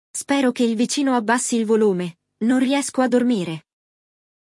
Neste episódio, você acompanhará a conversa entre dois amigos que falam sobre um novo vizinho que anda causando incômodo com o volume do som muito alto.
O Walk ‘n’ Talk Essentials Italiano é um formato de aula em áudio pensado para quem deseja aprender de maneira natural e envolvente.
• Ouve um diálogo realista em italiano, absorvendo o vocabulário e a estrutura da língua.
• Treina a pronúncia com exercícios de repetição guiados pelo professor.